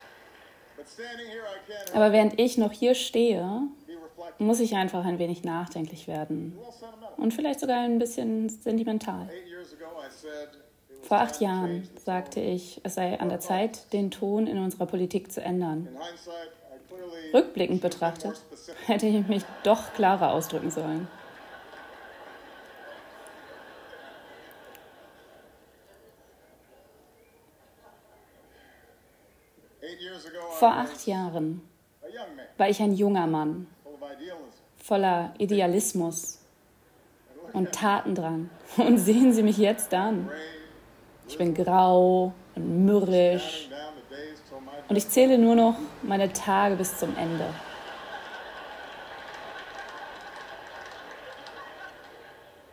Wir dolmetschen „live“ für Sie. Mit nur kleiner zeitlicher Verzögerung werden Worte Ihrer Redner:innen in die jeweilig benötigte Sprache übertragen.